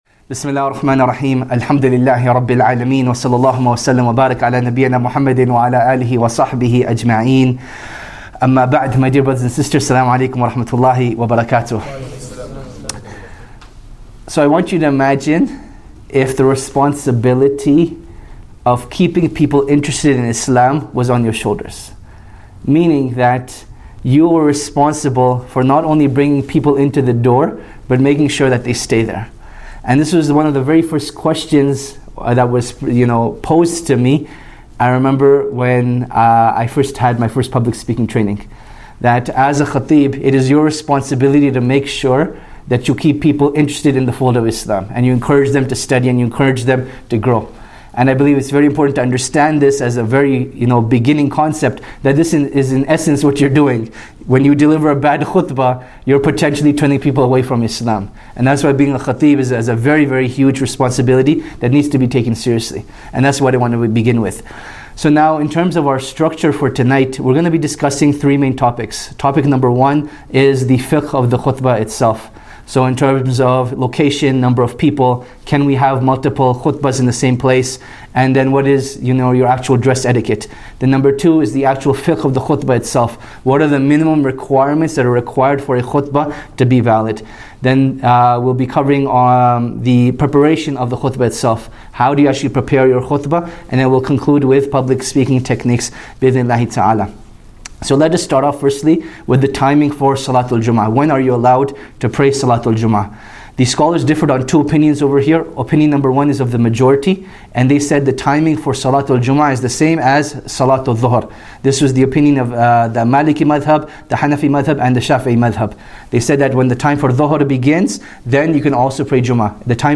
Khateeb training workshop